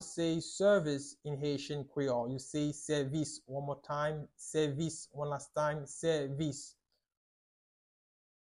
Listen to and watch “Sèvis” audio pronunciation in Haitian Creole by a native Haitian  in the video below:
27.How-to-say-Service-in-Haitian-Creole-–-Sevis-pronunciation.mp3